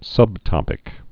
(sŭbtŏpĭk)